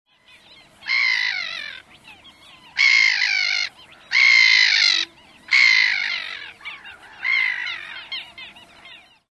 Gavina vulgar (Larus ridibundus)
larus-ridibundus.mp3